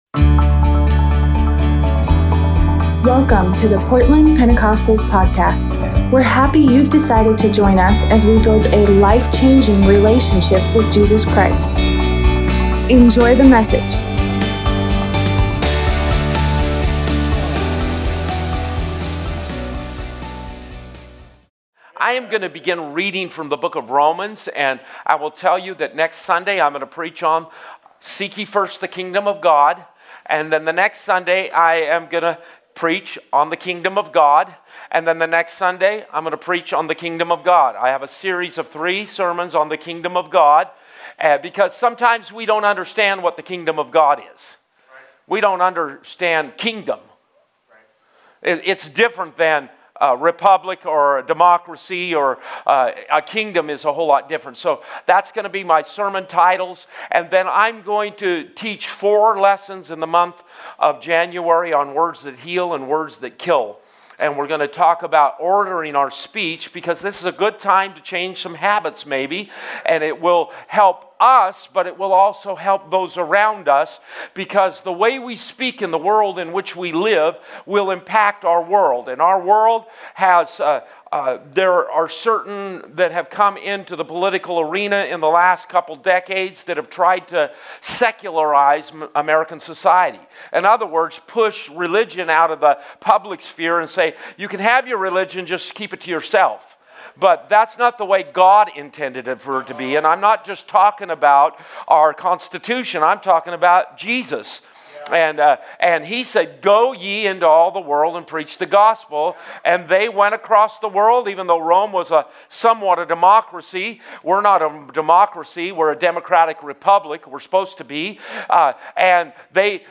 Tuesday night Bible study